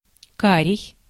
Ääntäminen
IPA: [ma.ʁɔ̃]